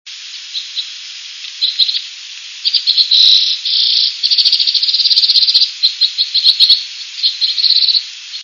White-winged Crossbill
White-winged Crossbill Mt. Albert, Quebec, Canada, 9/2/00 (33kb) wave676 Index October 2000 : Holiday weekend I was lucky enough to run into a flock of 20-30 bluebirds flying south along the very top of the Kittatiny Ridge.
crossbills_676.wav